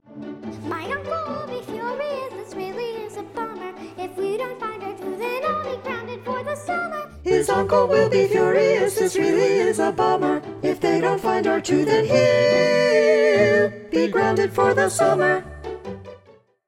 Hear bars 46-56 with all voices except tenor (this is what will be played at your audition)
sw125-19_NoTenor.mp3